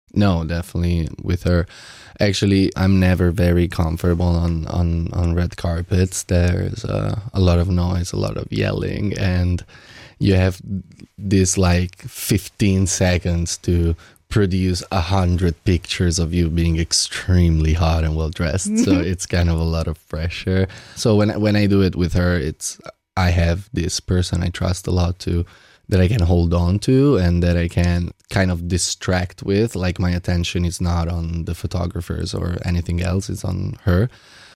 Interview mit Damiano David
Italienischer Sänger im Interview Damiano David über Rote Teppiche und seine Freundin